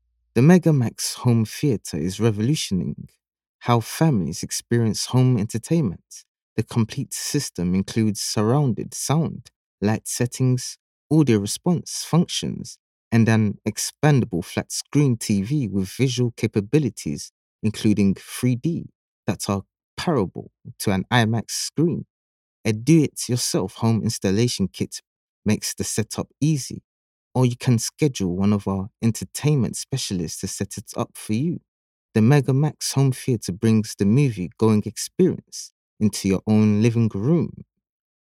Never any Artificial Voices used, unlike other sites.
Foreign & British Male Voice Over Artists & Actors
English (Caribbean)
Adult (30-50) | Yng Adult (18-29)